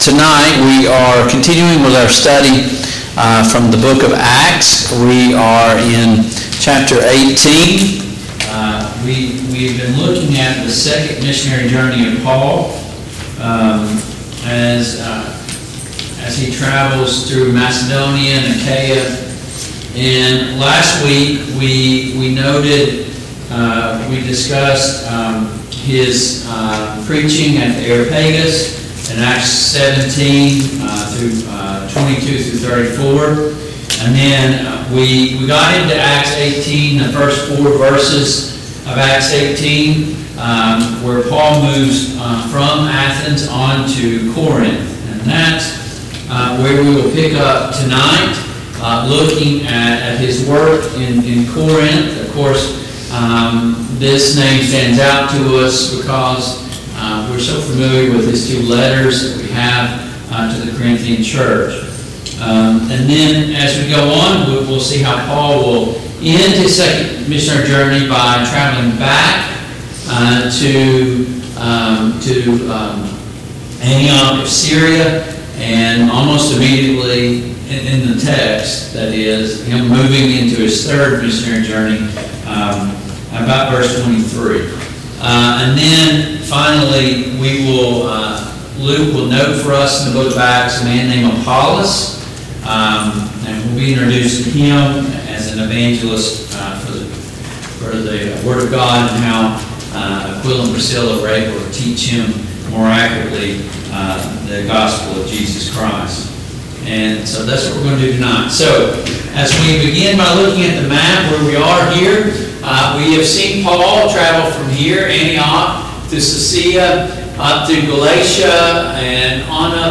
Passage: Acts 18:5-23 Service Type: Mid-Week Bible Study